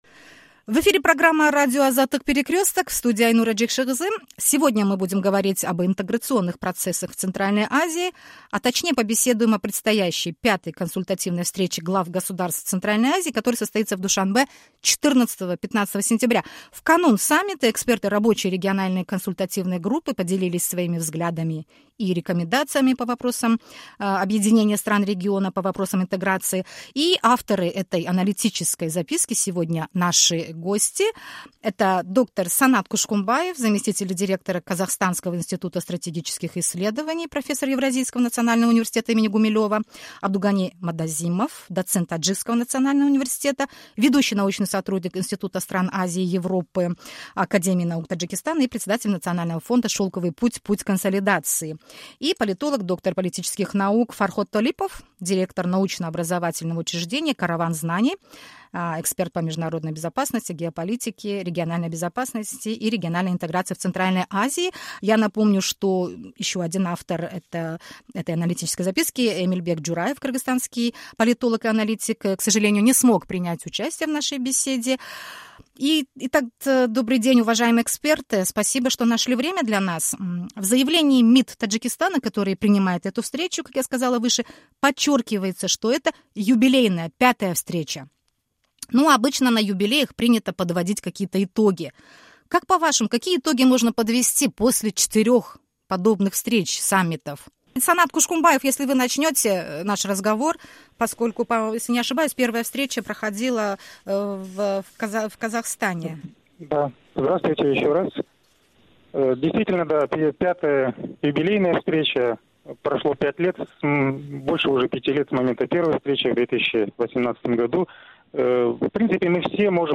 В канун V Консультативной встречи глав государств Центральной Азии, которая состоится в Душанбе 14-15 сентября, эксперты поделились своими взглядами и рекомендациями по вопросам интеграции стран региона. Авторы аналитической записки выступили в эфире Радио Азаттык (Кыргызской редакции Радио Озоди)